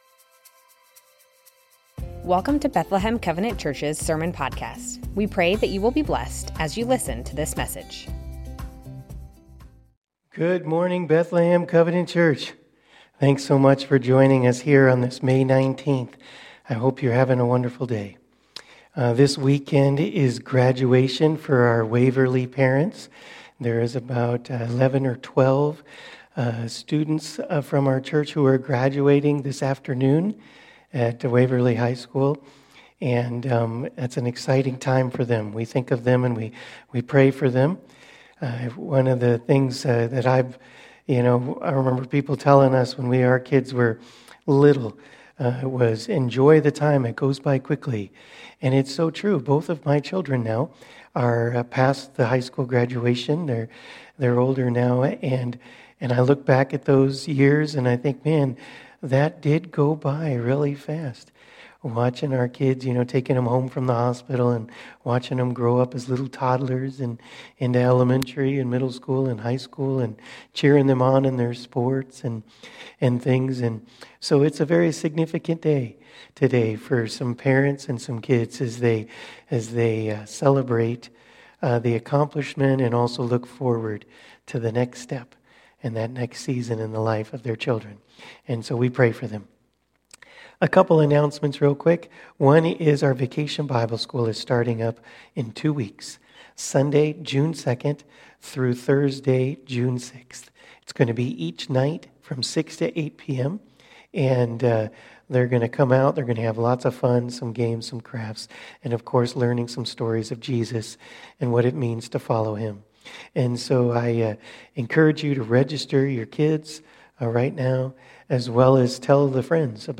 Bethlehem Covenant Church Sermons James - It's in how you treat people May 19 2024 | 00:32:19 1x 00:00 / 00:32:19 Subscribe Share Spotify RSS Feed Share Link Embed